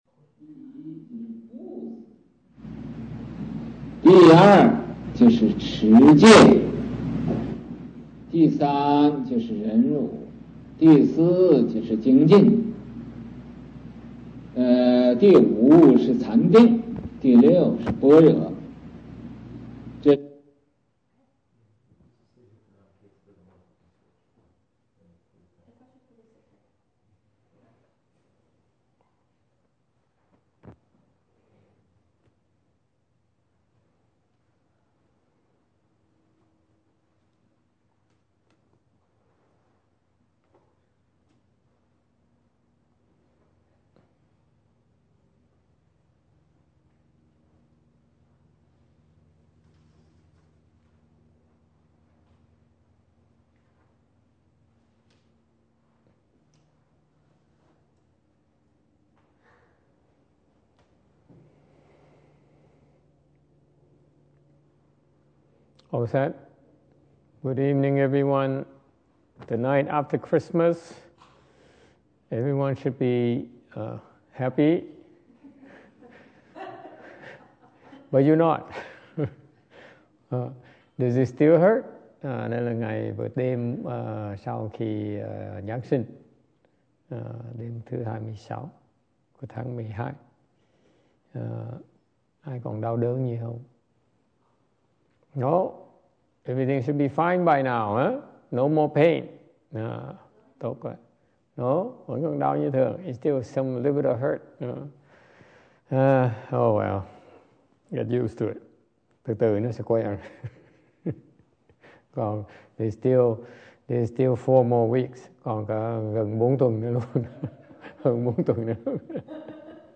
2014년 12월 26일 선칠 법문 https